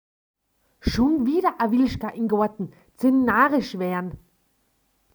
Vom Iseltal bis Hopfgarten, in Kals und im Lienzer Talboden sagt man also Wilschga und meint einen Maulwurf. Wer gerne wissen möchte, wie so ein Wilschga gesprochen klingt, kann es sich hier anhören: